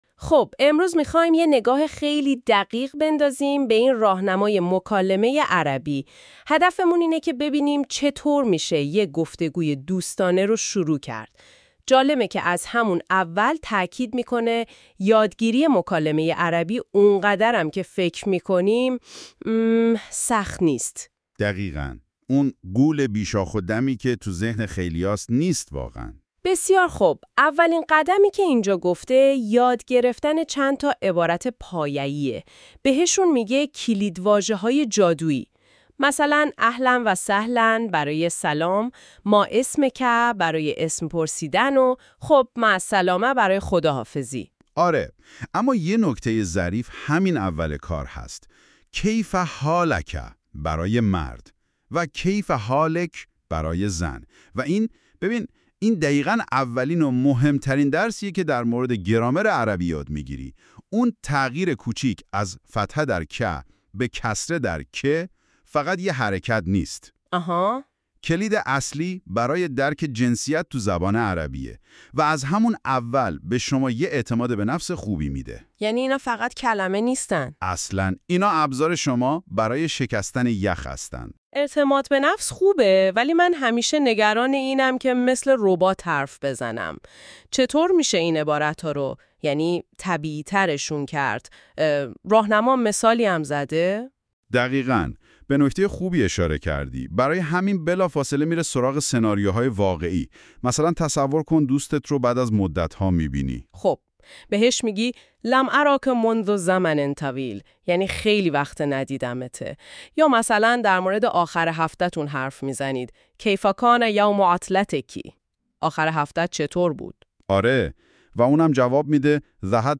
arabic-conversation-between-two-friends.mp3